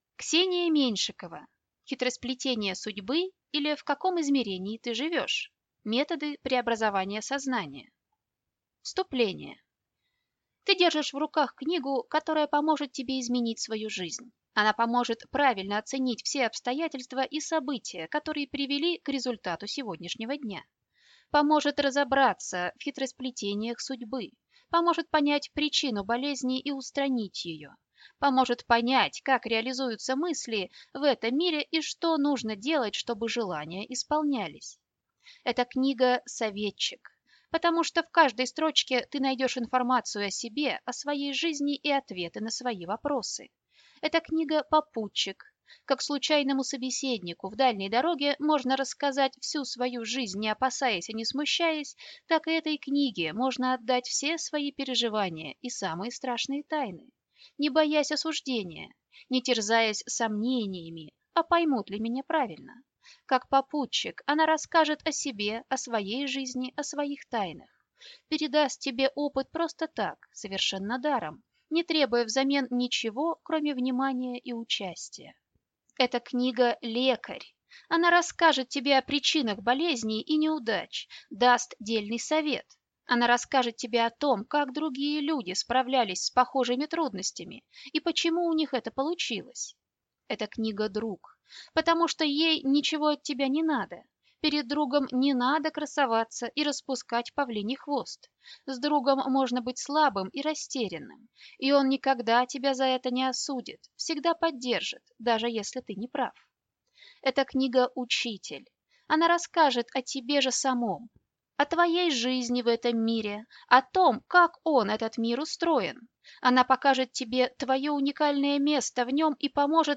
Аудиокнига Хитросплетения судьбы, или В каком измерении ты живешь? Методы преобразования сознания | Библиотека аудиокниг